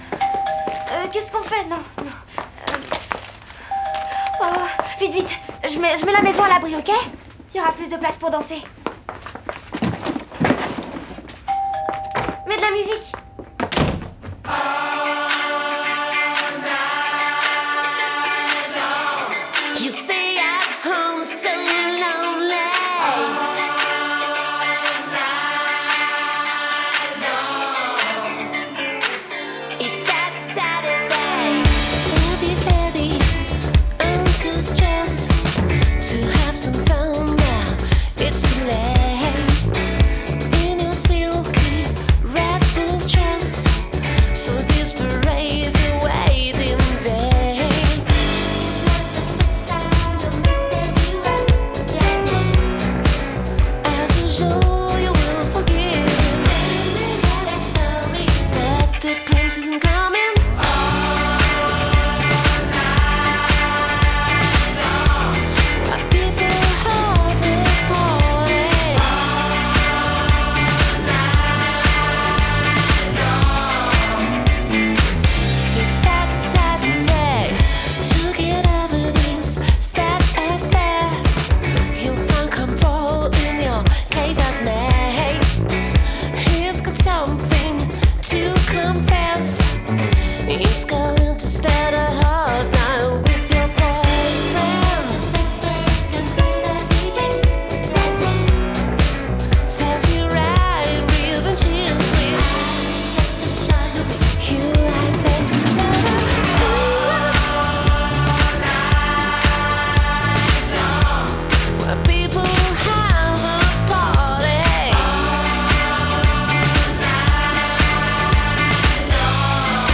Hello house, hip-hop, funk!